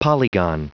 Prononciation du mot polygon en anglais (fichier audio)
Prononciation du mot : polygon